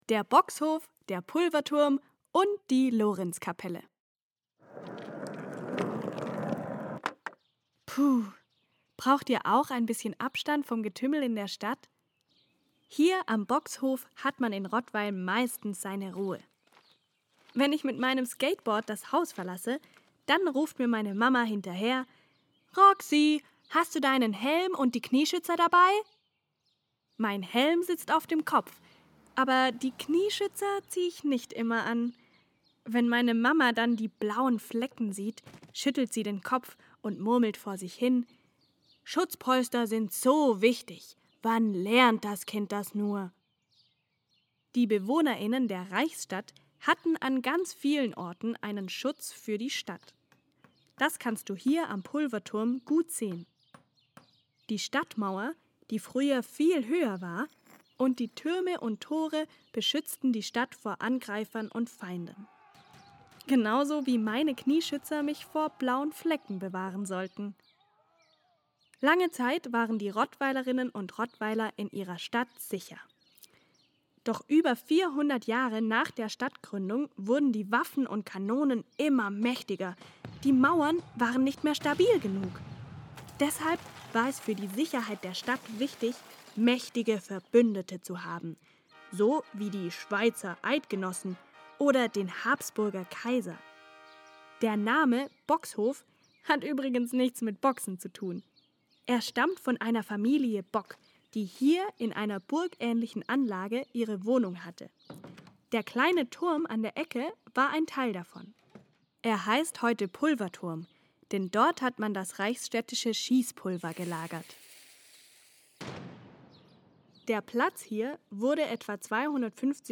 Audioguide Rottweil | 04. Bockshof, Pulverturm und Lorenzkapelle